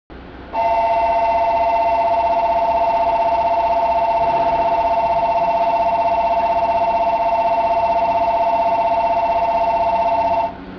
E127系車載発車ベル
ワンマン運転に使用する際に用いる発車ベル。100番台にて収録。